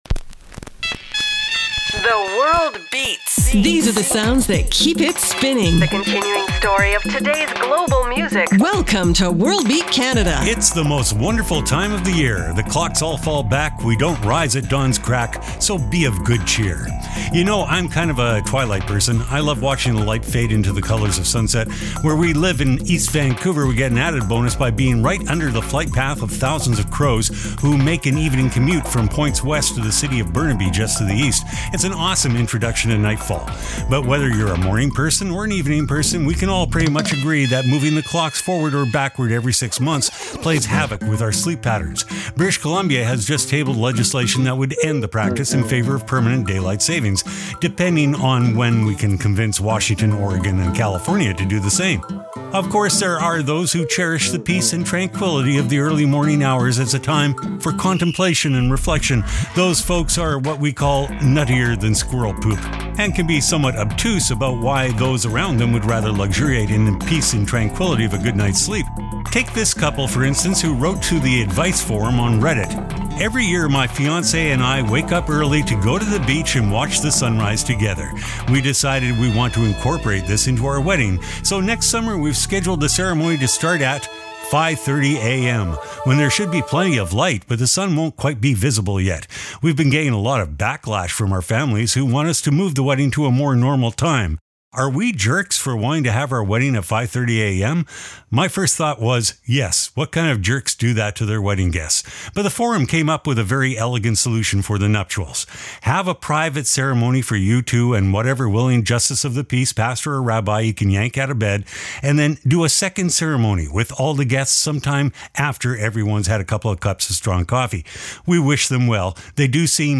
exciting global music alternative to jukebox radio